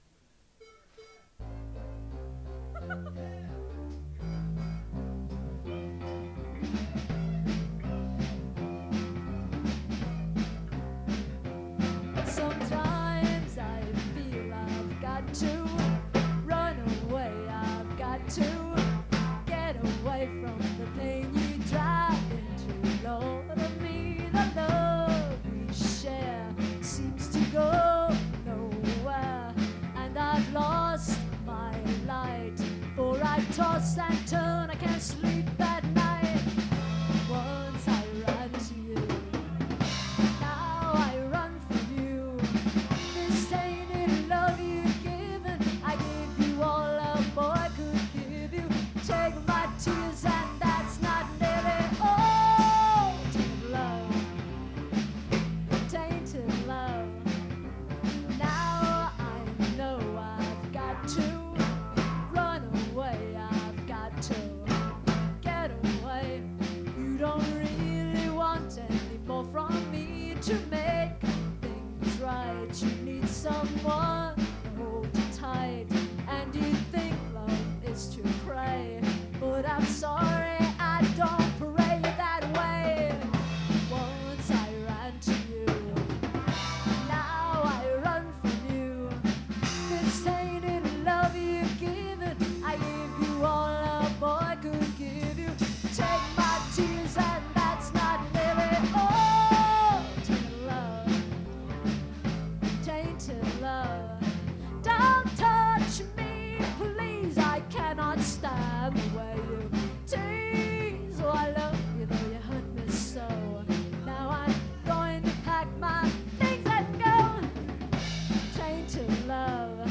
with a very bluesy ending.